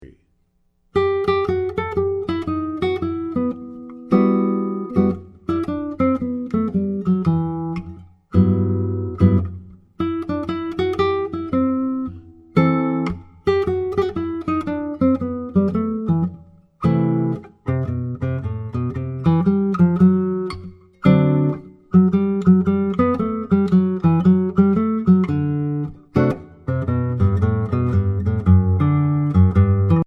Voicing: Guitar